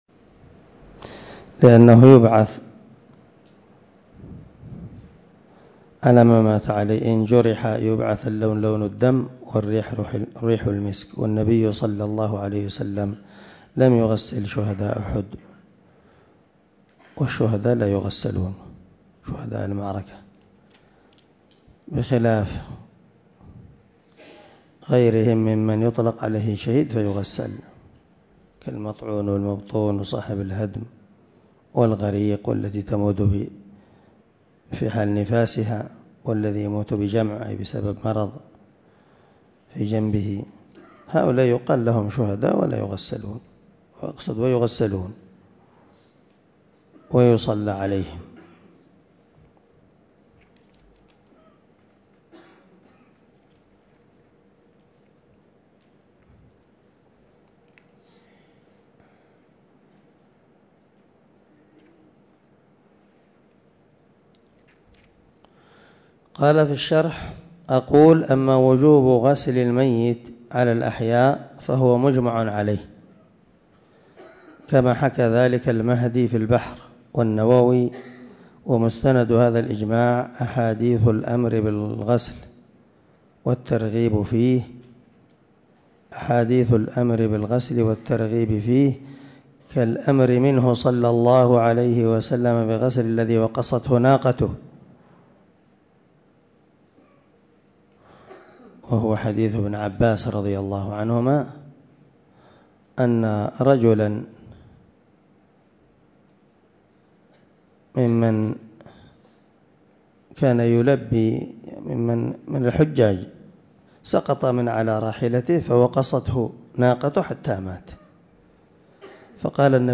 درس أحكام غسل الميت
درس-أحكام-غسل-الميت.mp3